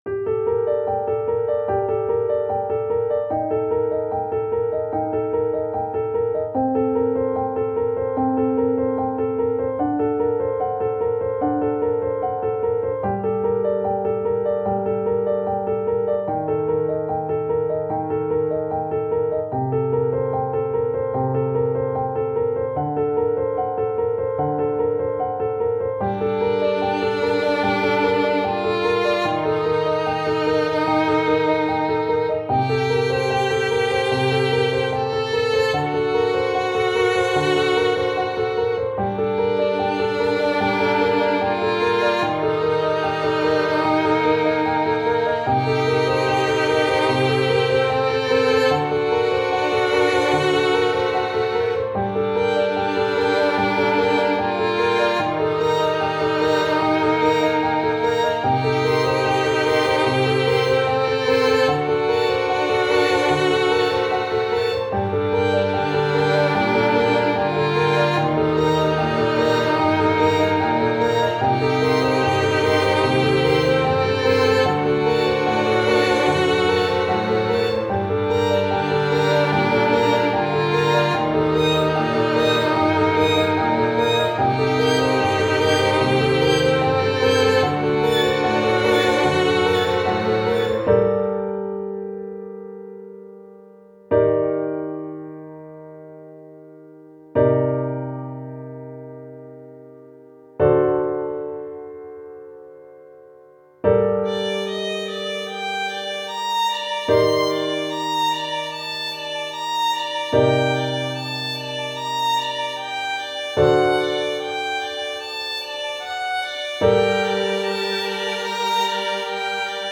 -oggをループ化-   暗い ピアノ 3:03 mp3